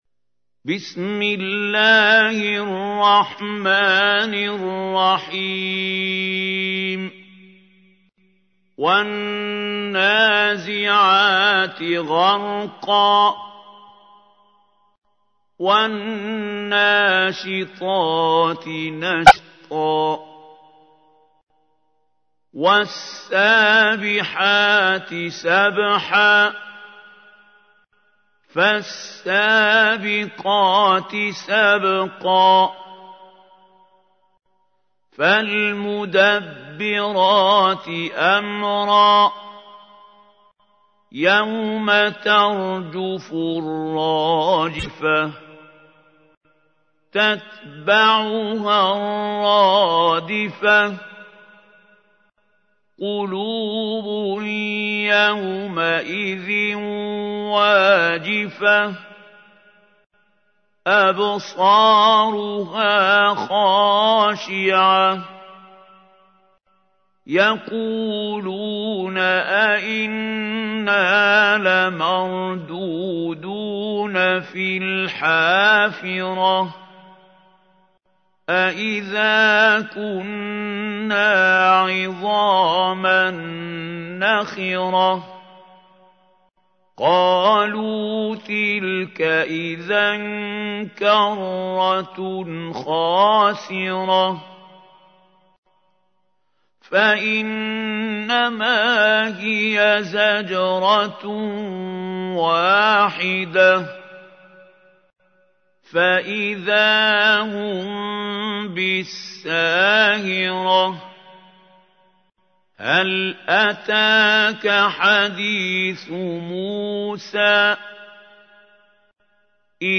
تحميل : 79. سورة النازعات / القارئ محمود خليل الحصري / القرآن الكريم / موقع يا حسين